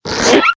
toon_decompress.ogg